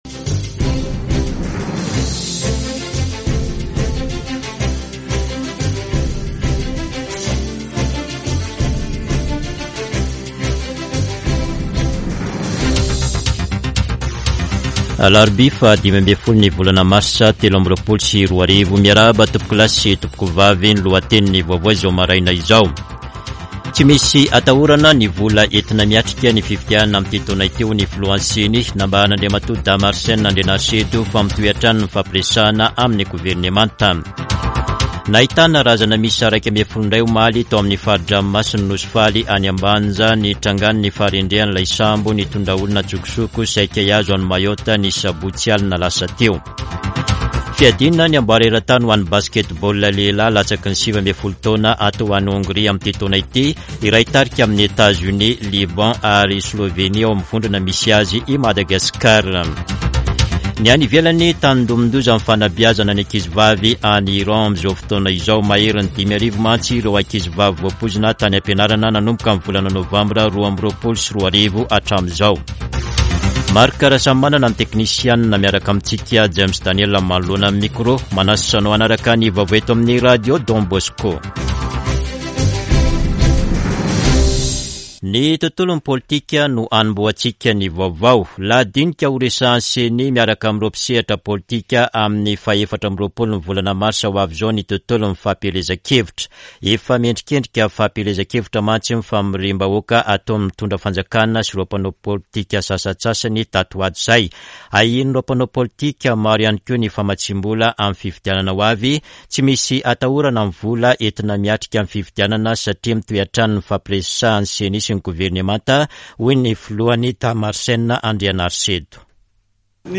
[Vaovao maraina] Alarobia 15 marsa 2023